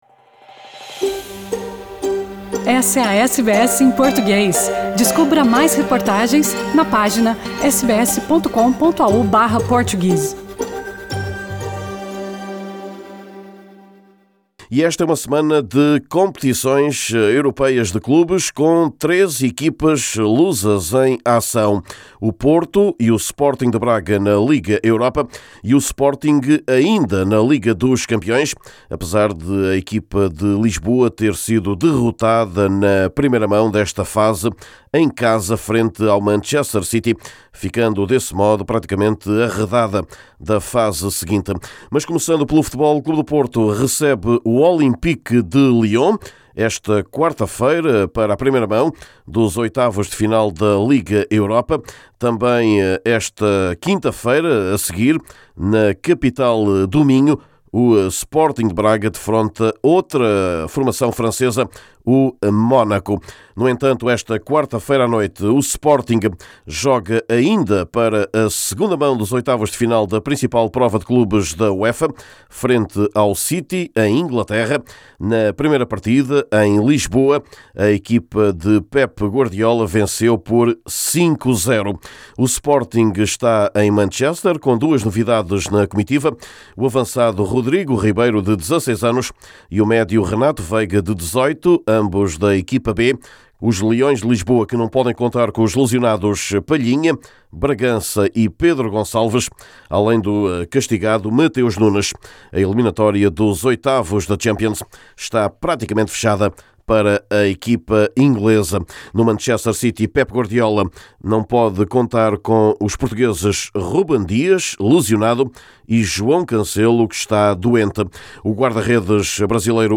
Escutamos o treinador do FC Porto, Sérgio Conceição, que recebe uma equipa francesa – tal como o Braga, mas para a Liga Europa.